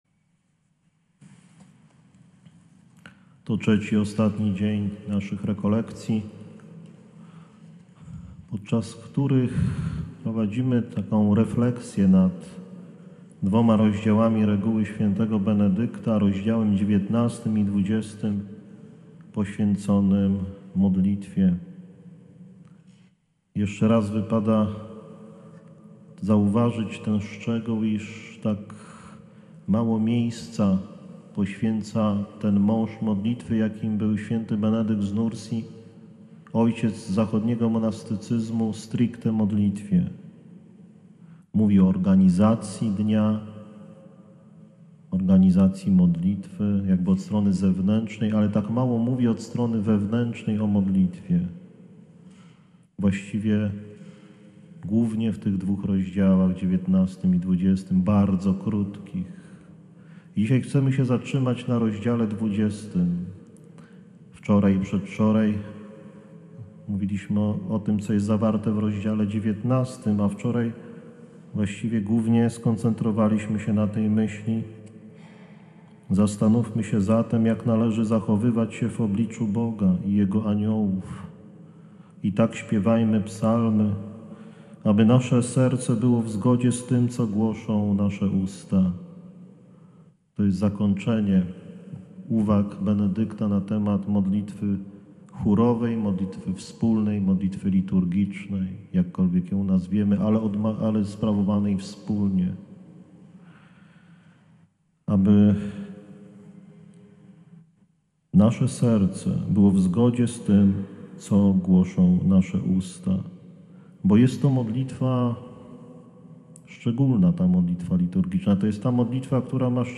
REKOLEKCJE ADWENTOWE 14-16 XII 2015